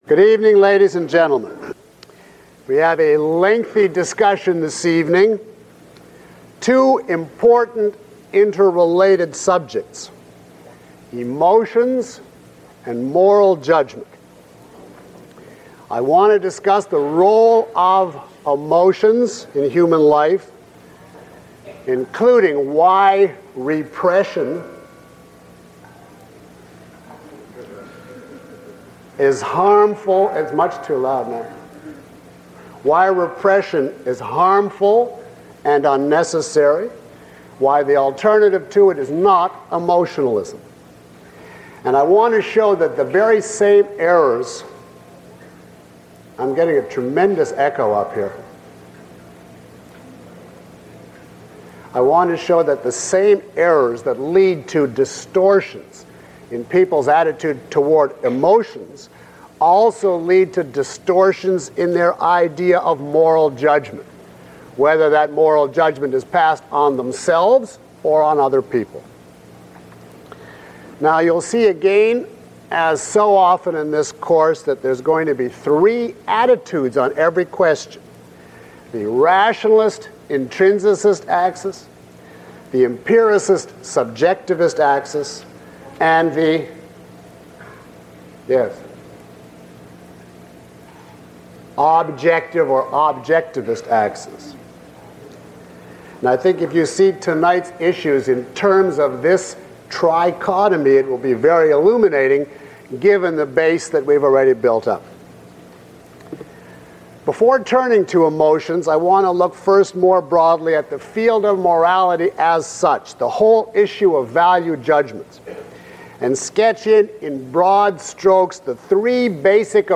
This course features lecture material by Dr. Peikoff as well as exercises and demonstrations from the live audience.